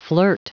Prononciation du mot flirt en anglais (fichier audio)
Prononciation du mot : flirt